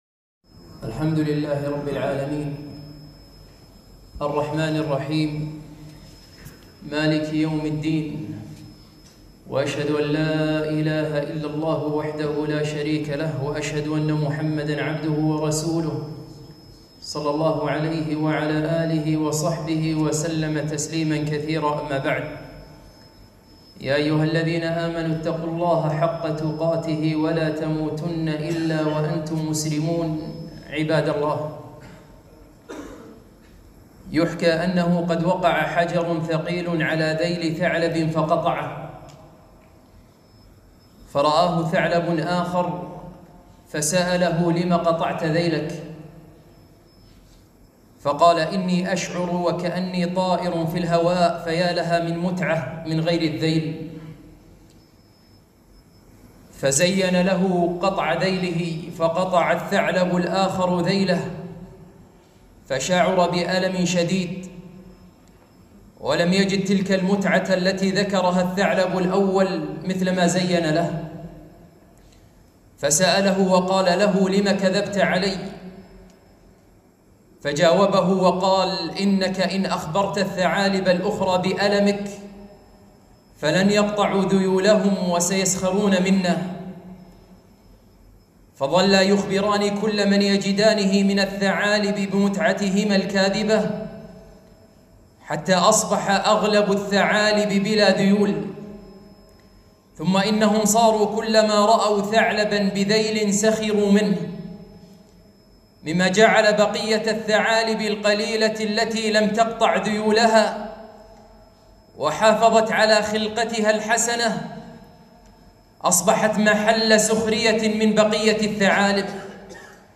خطبة - ذيـول الثعـالـب